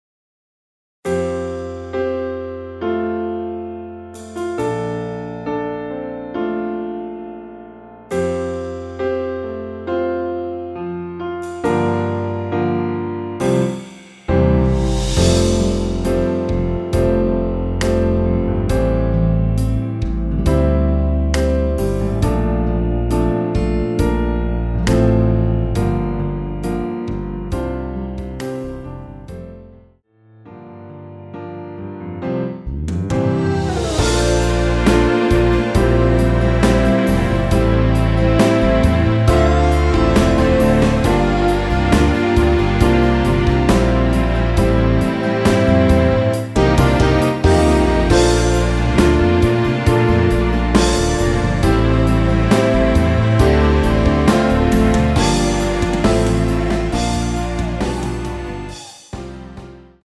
(-1)내린2절 부터 시작 하게 편곡 하였습니다.(미리듣기 참조)
앨범 | O.S.T
앞부분30초, 뒷부분30초씩 편집해서 올려 드리고 있습니다.
곡명 옆 (-1)은 반음 내림, (+1)은 반음 올림 입니다.